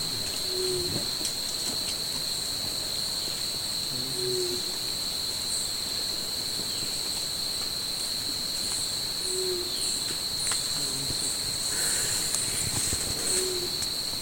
Paloma Montera Castaña (Geotrygon montana)
Nombre en inglés: Ruddy Quail-Dove
Fase de la vida: Adulto
Localidad o área protegida: La Minga ecolodge
Condición: Silvestre
Certeza: Vocalización Grabada
vocalizando cerca de una calle interna de la reserva